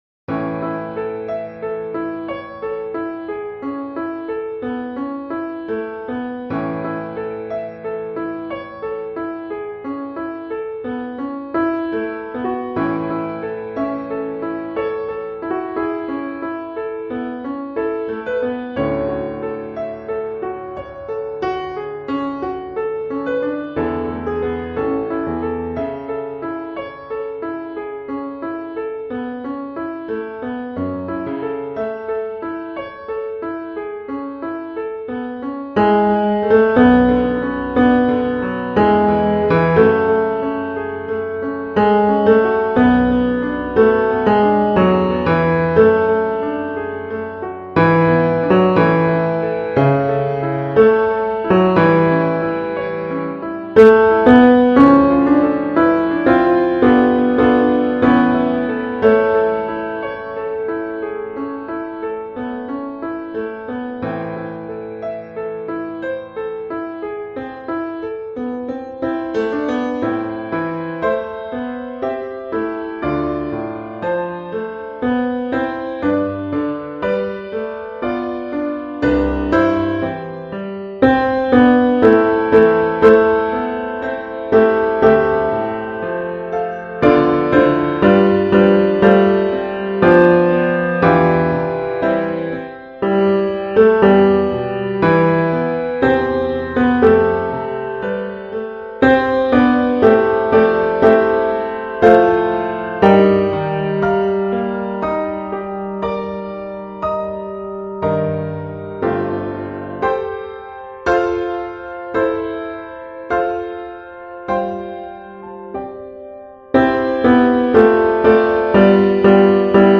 Let Me Hide Myself in Thee – Bass